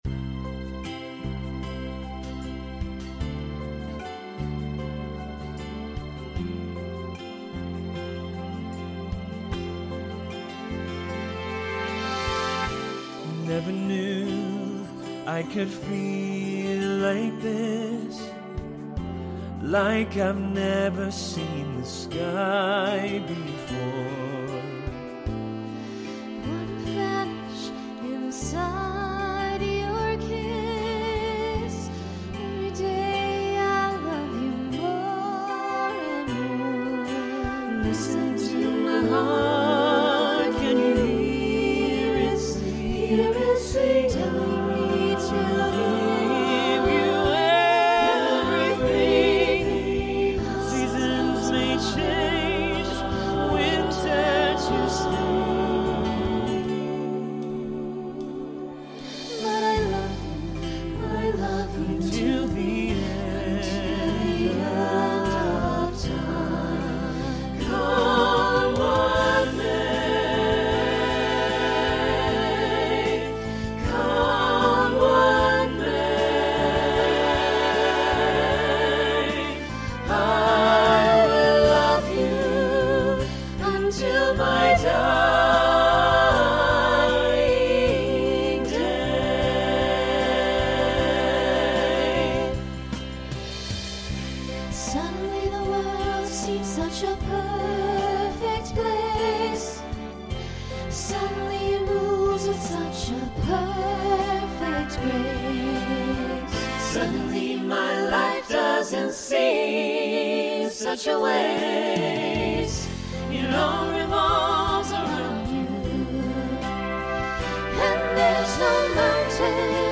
Voicing SATB Instrumental combo Genre Broadway/Film
Show Function Ballad